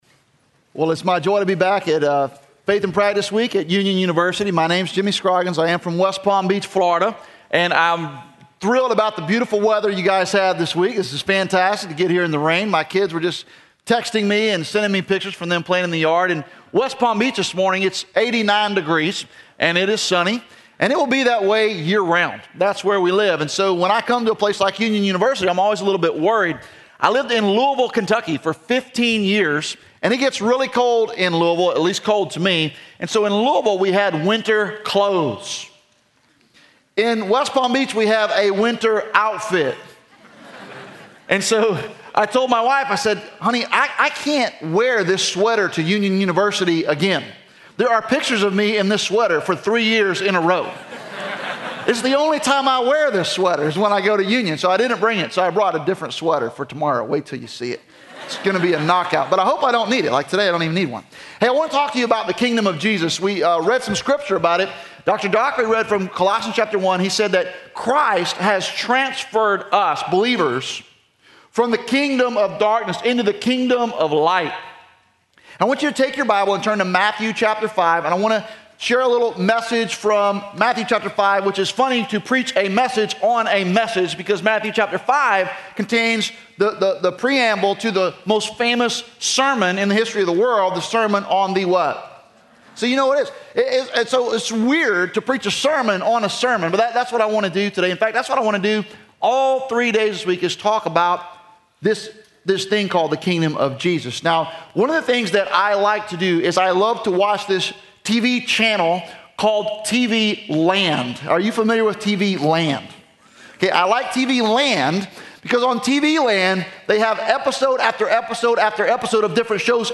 Faith in Practice Chapel:
Address: "The Kingdom of Jesus" from Matthew 5